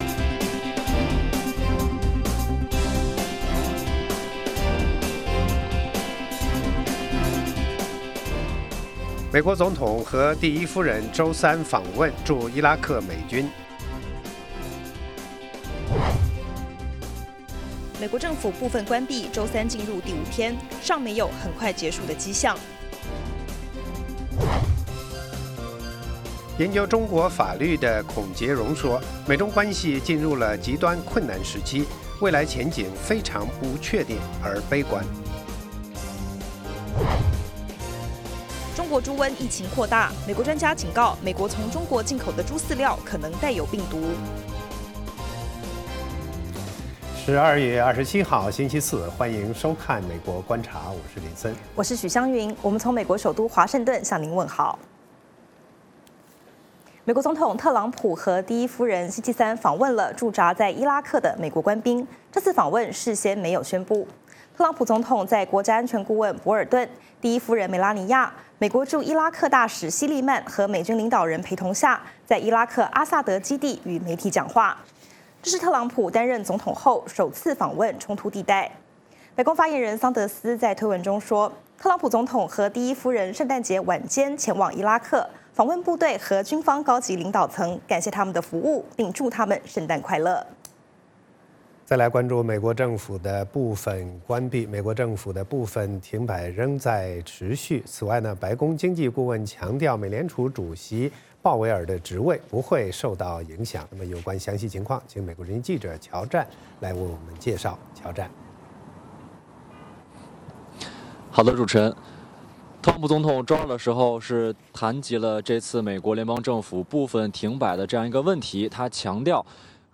北京时间早上6-7点广播节目，电视、广播同步播出VOA卫视美国观察。 “VOA卫视 美国观察”掌握美国最重要的消息，深入解读美国选举，政治，经济，外交，人文，美中关系等全方位话题。节目邀请重量级嘉宾参与讨论。